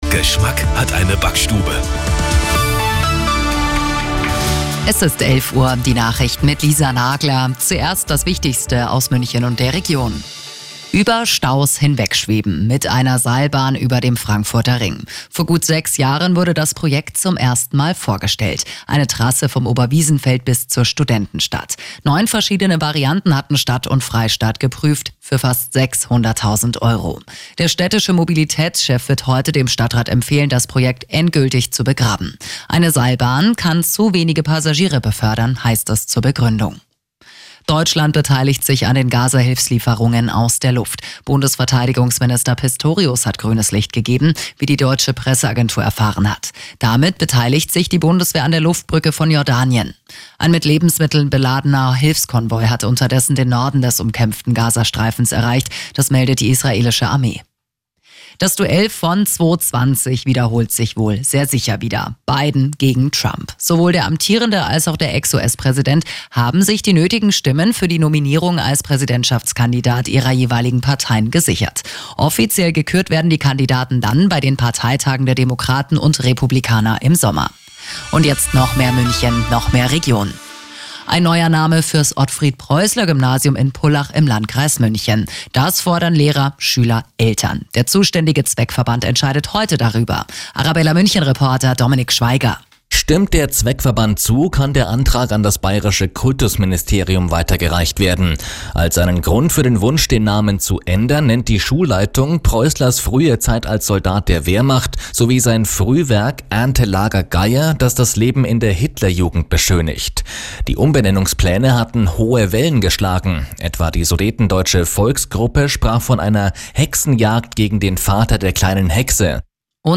Die Arabella Nachrichten vom Mittwoch, 13.03.2024 um 15:36 Uhr - 13.03.2024